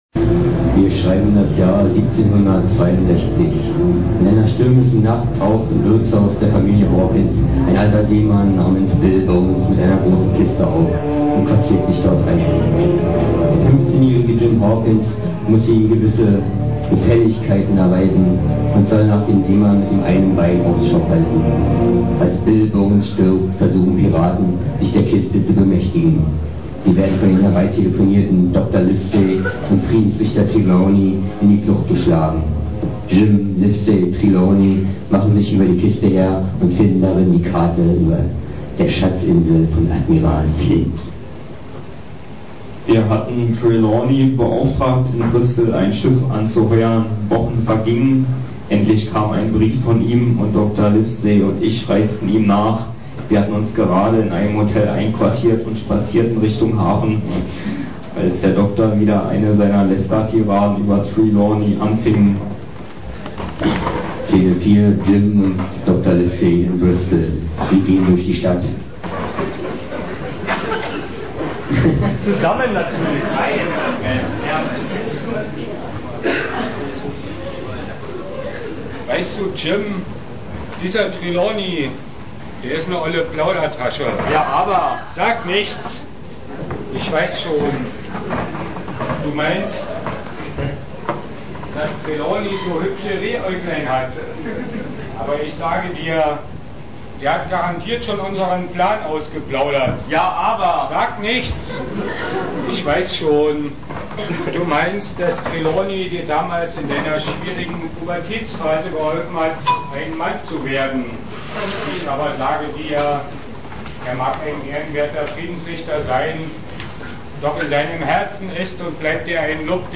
(aufgef�hrt am 15.1.04)